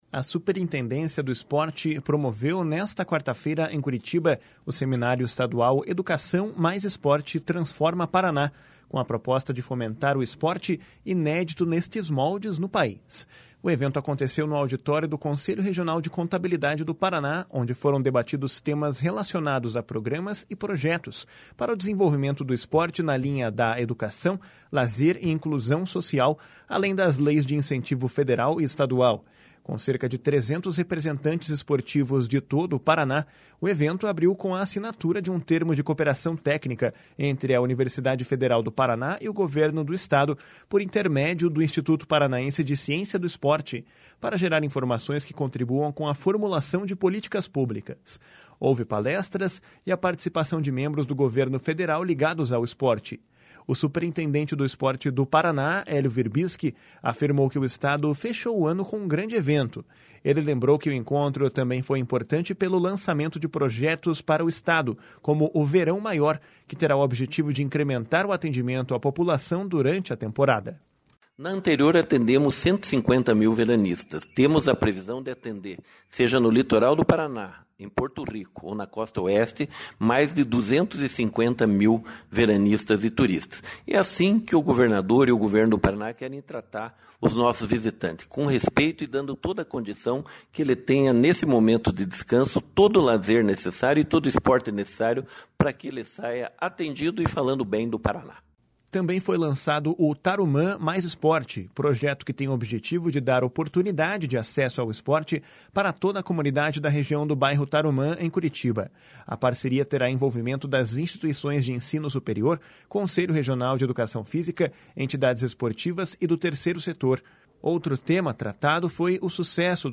// SONORA HELIO WIRBISKI //